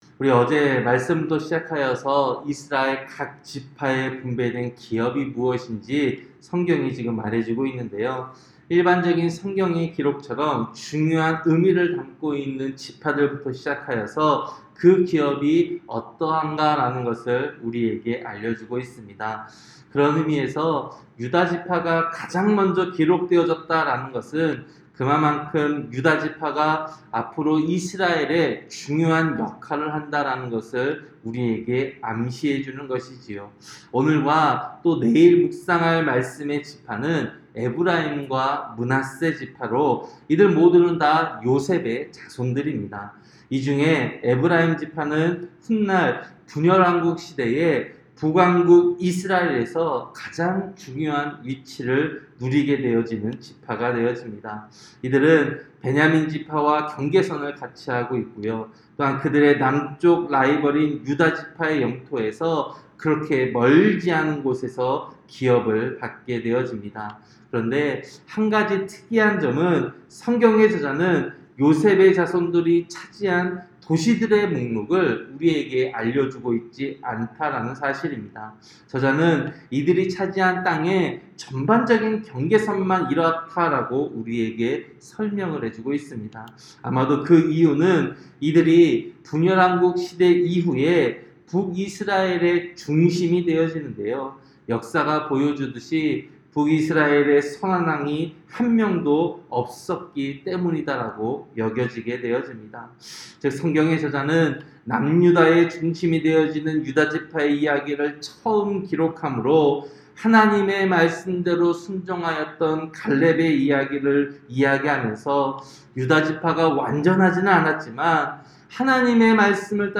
새벽설교-여호수아 16장